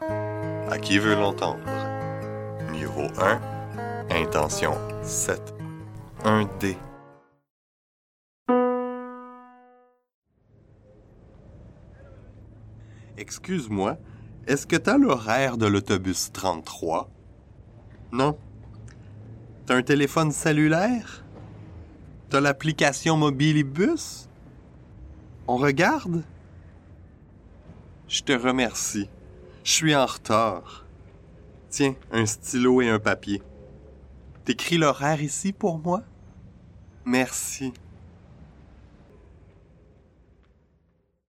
Pronoms personnels conjoints : Associer [t] au pronom sujet tu devant un mot commençant par une voyelle ou un h muet o